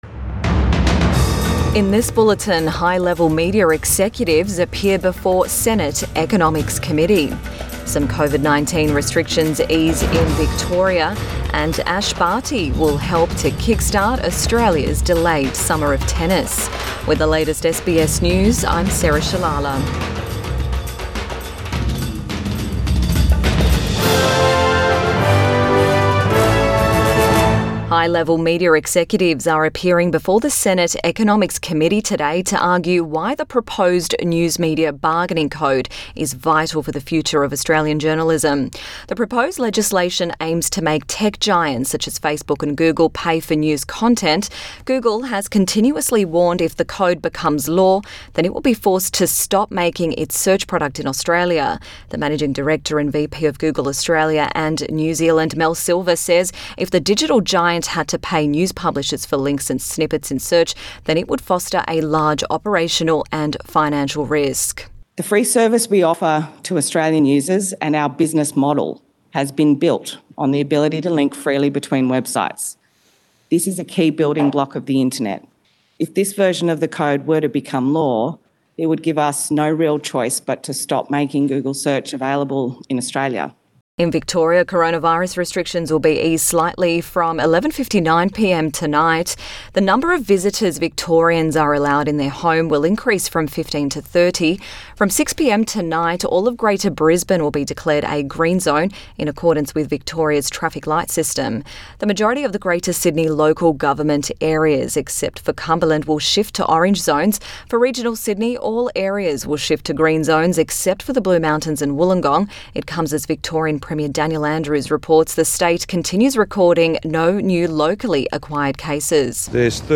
Midday bulletin 22 January 2021